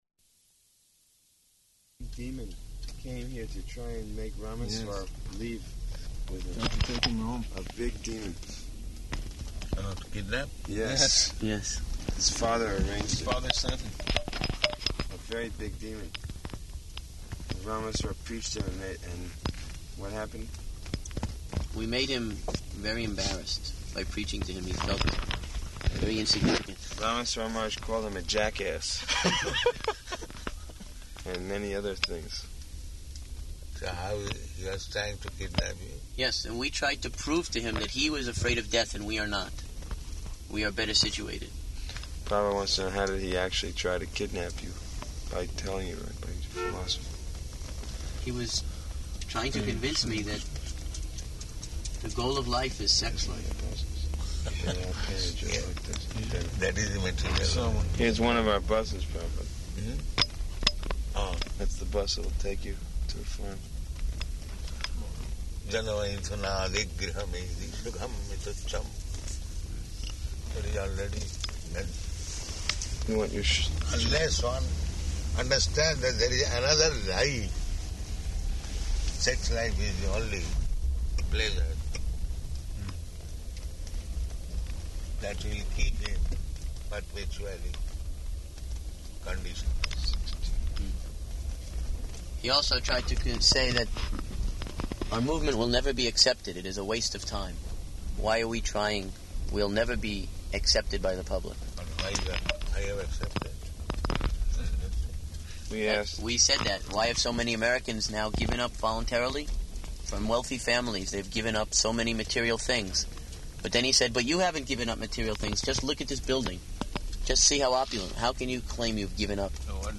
July 14th 1976 Location: New York Audio file
[in car]